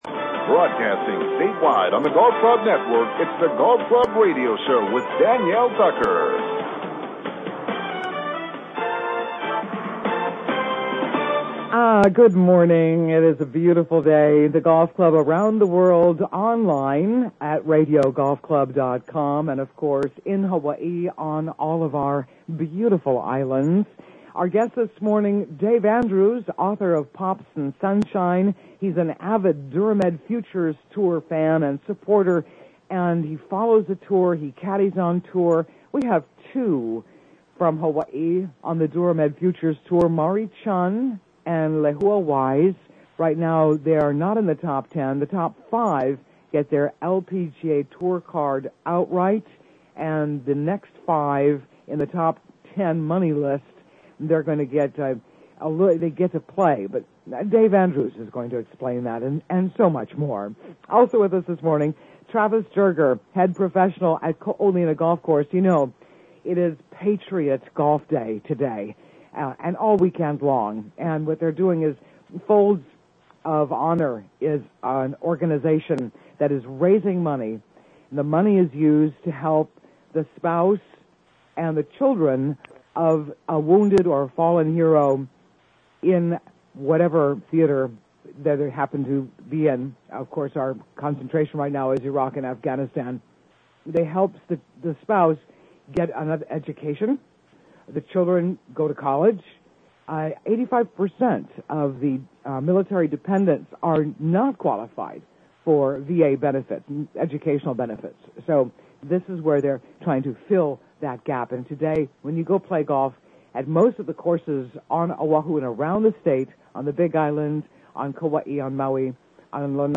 "The Golf Club" Radio Show In Hawaii